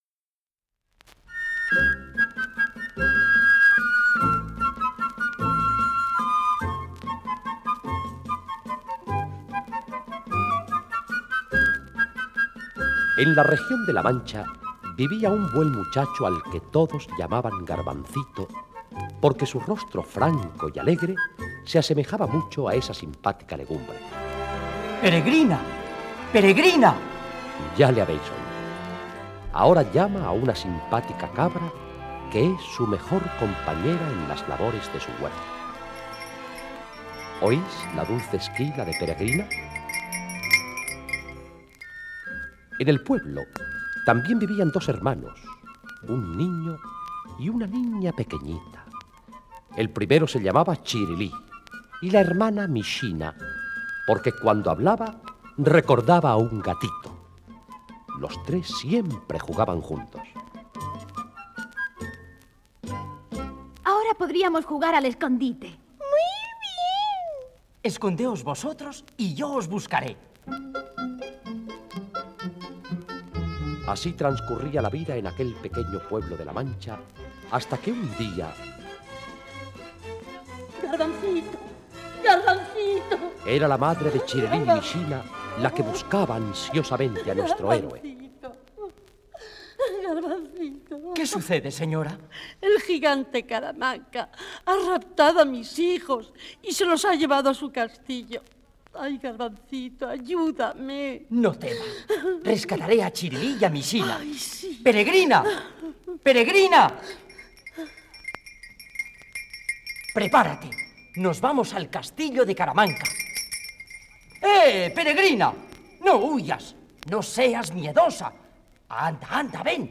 Teatro invisible de Radio Nacional de España en Barcelona
garbancito. cuento.mp3